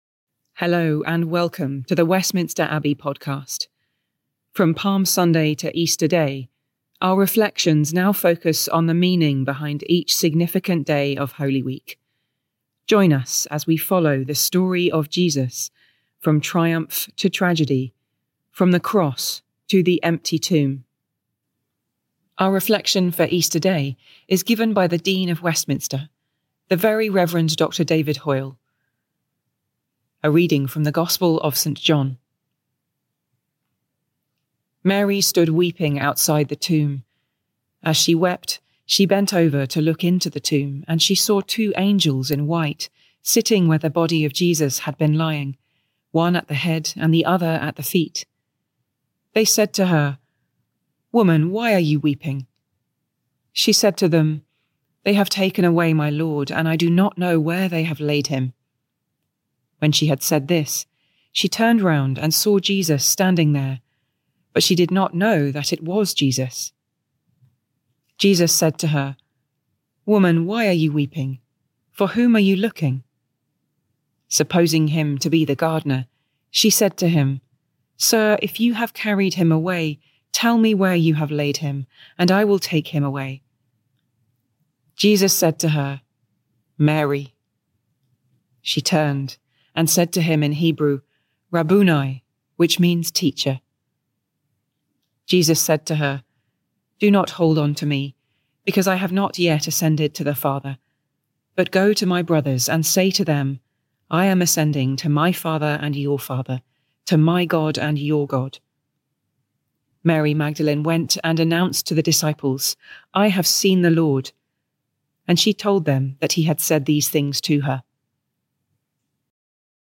Listen to a reflection for Easter Sunday by the Very Reverend Dr David Hoyle, Dean of Westminster.